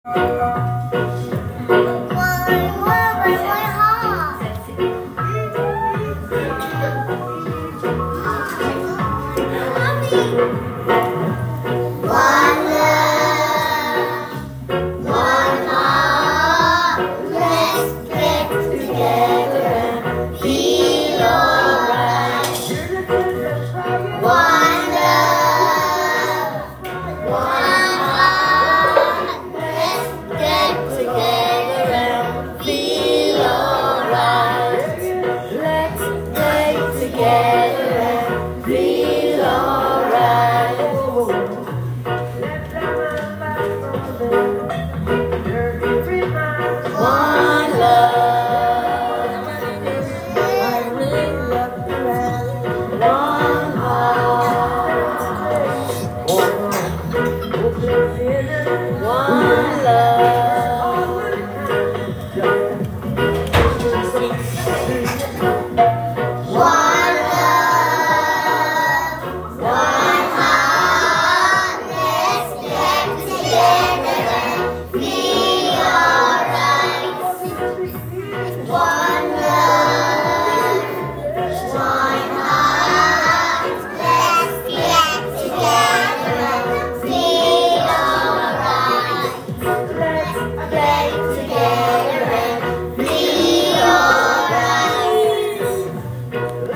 For Black History Month the children in Reception focused on a song about unity. One Love - by Bob Marley.